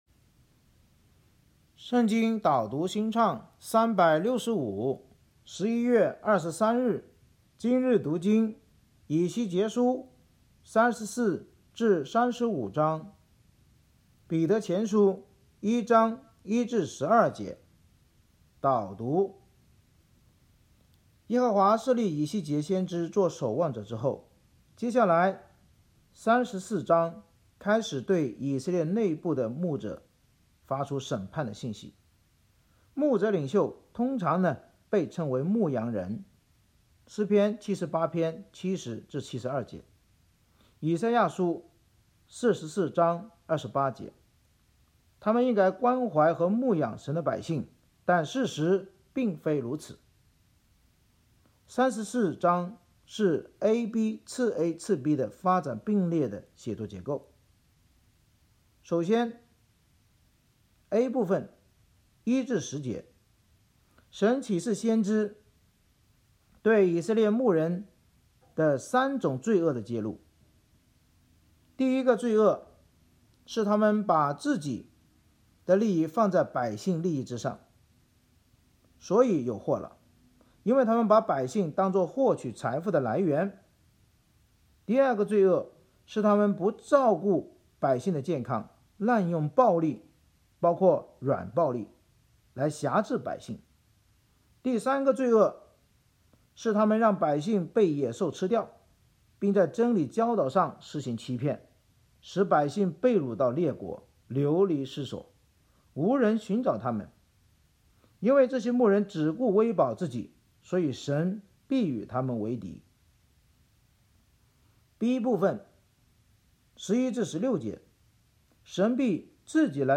圣经导读&经文朗读 – 11月23日（音频+文字+新歌）